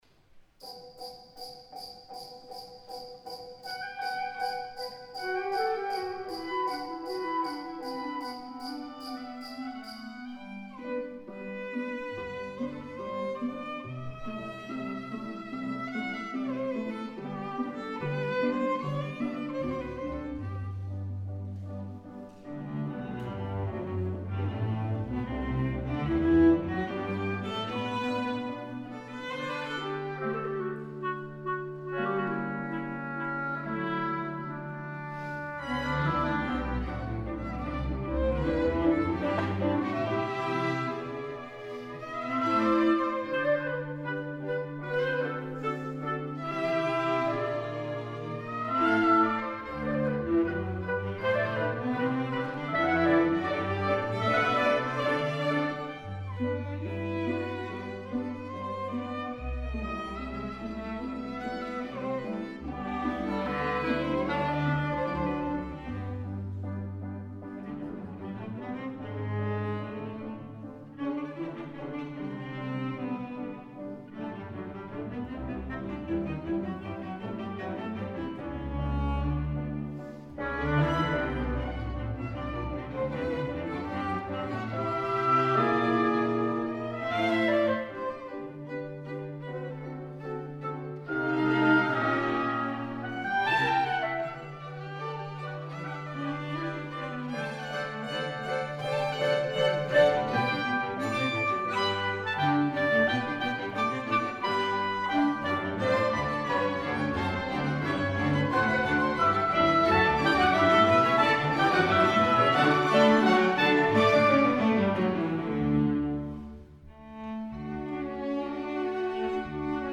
La 4ème symphonie est jouée dans la version retranscrite en 1921 par Erwin Stein pour 12 musiciens : quatuor à cordes, contrebasse, flûte, hautbois,...
! Le concert a du se dérouler sans public !